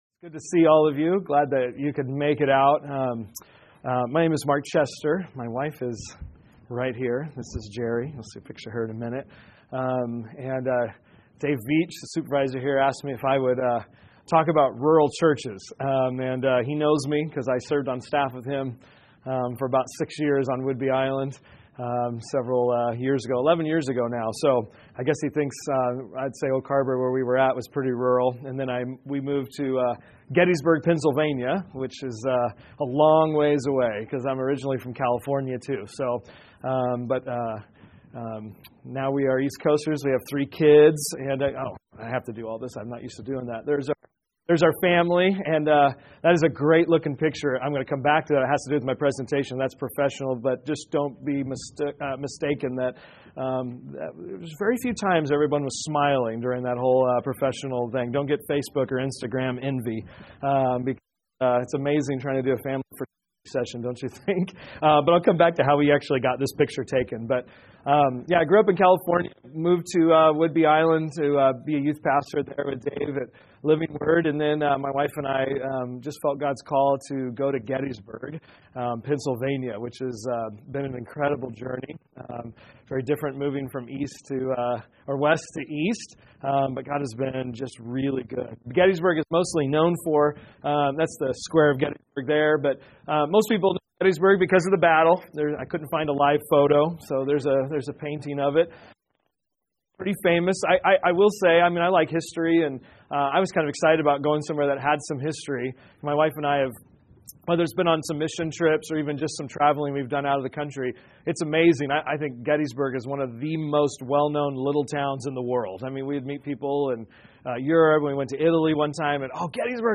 Workshop: Rural churches offer great ministry opportunities